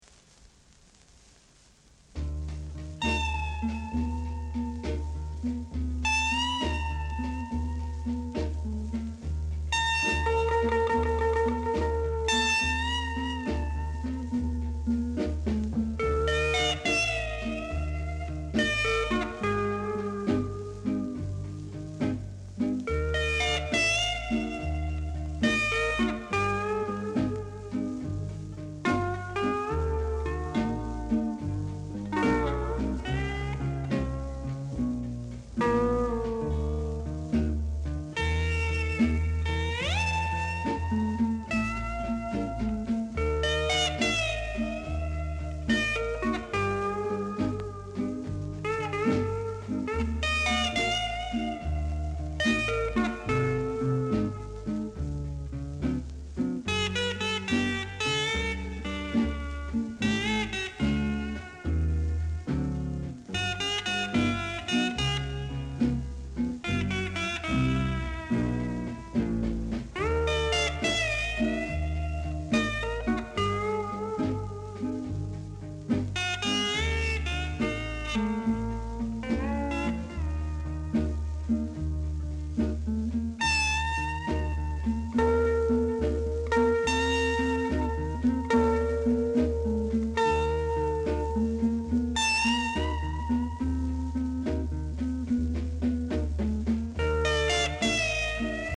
Jazz Inst
Mega rare! great jazz inst w-sider!